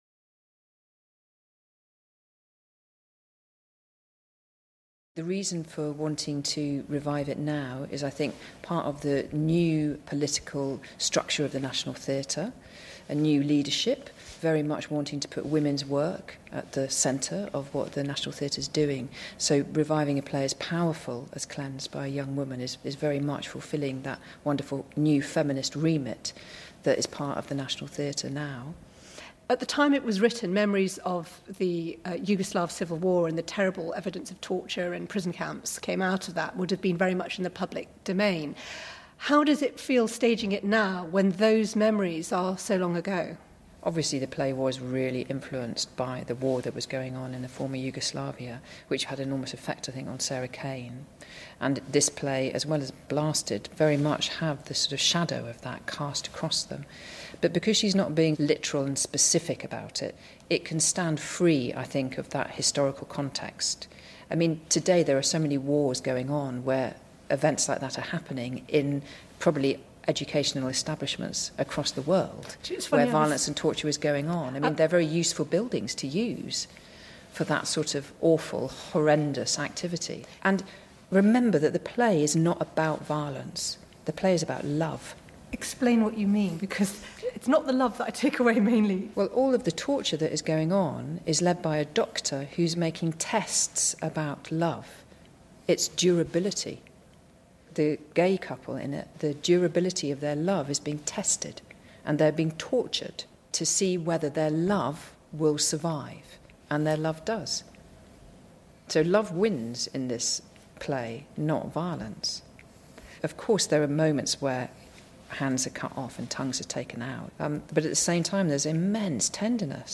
In an interview for the BBC strand Front RowMitchell said those who focus on the violence are missing the point: